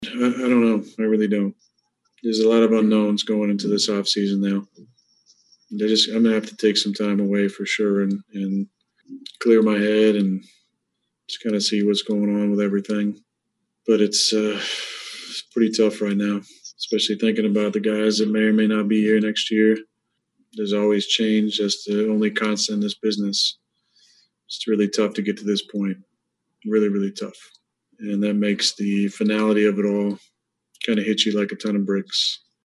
Rodgers also became very pensive talking about how the team, and more interestingly, he will move forward to 2021 and beyond.